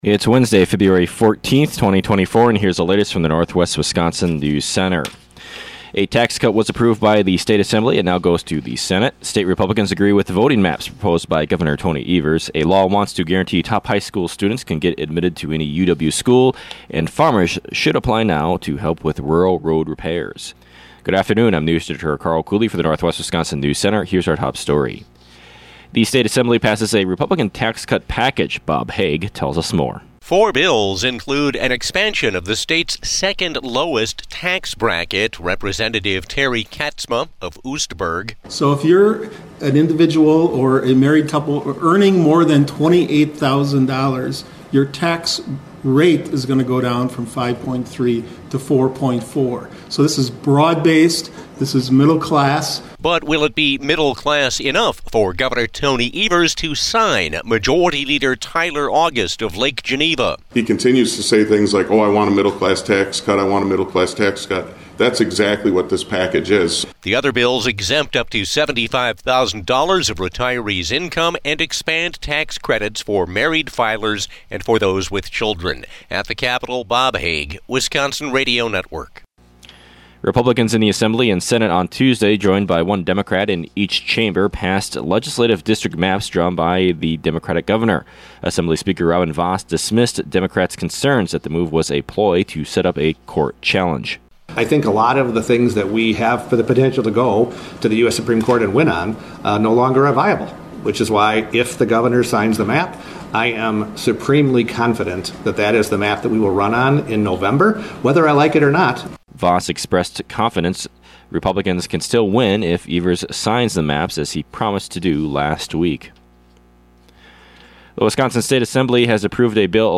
PM NEWSCAST – Wednesday, Feb. 14, 2024 | Northwest Builders, Inc.